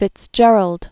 (fĭts-jĕrəld), Edward 1809-1883.